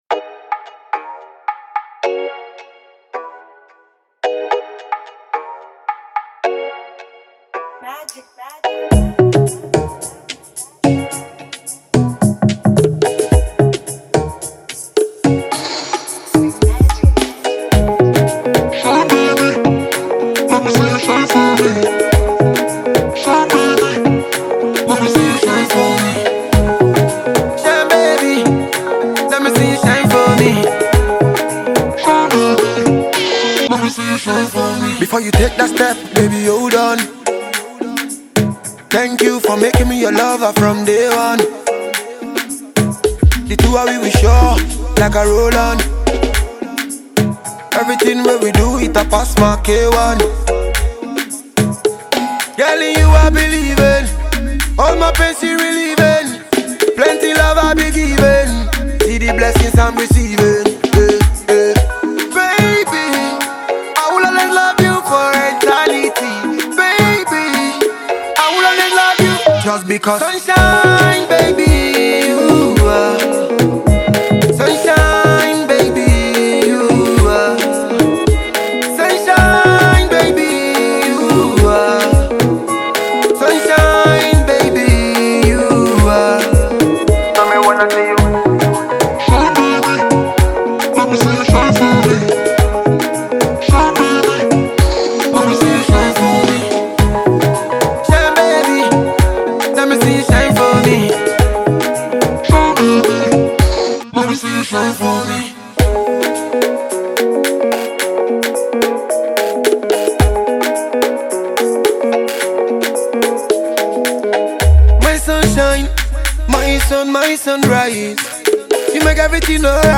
Dancheall
packs super vibes on the new single